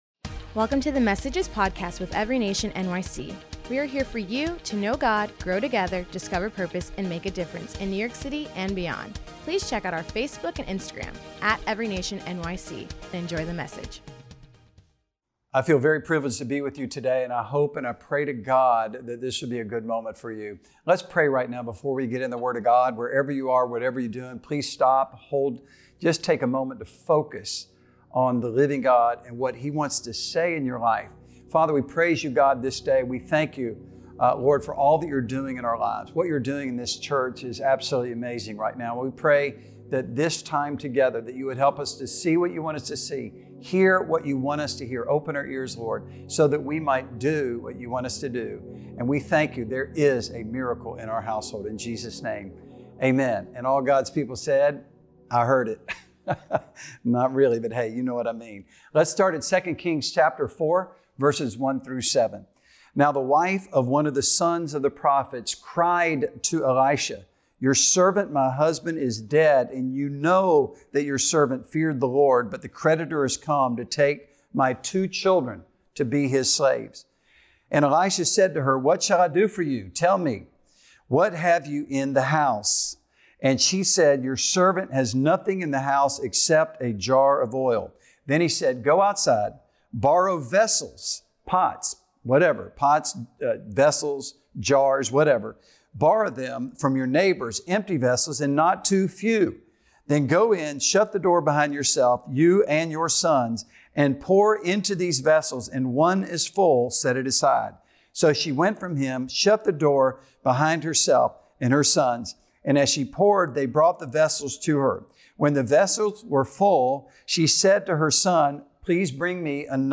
Miracle In Your House | October 24th Sermon